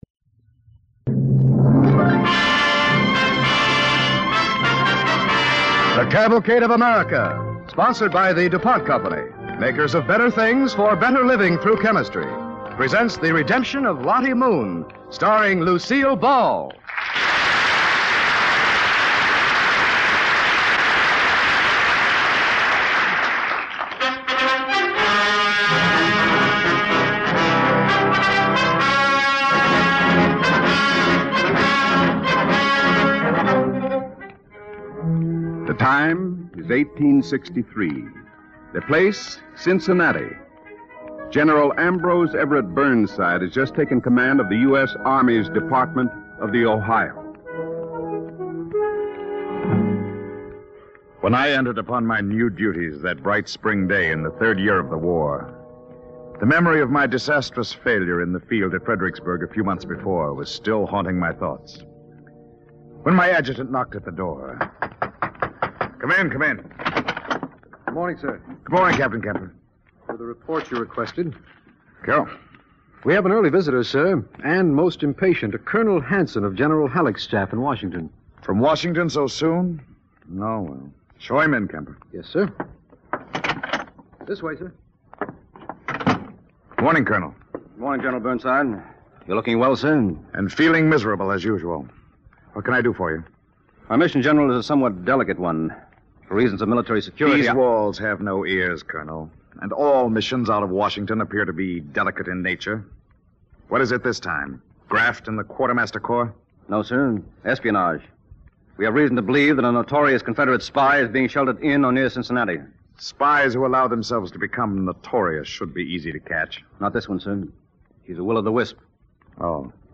Cavalcade of America Radio Program
starring Lucielle Ball and Berry Kroeger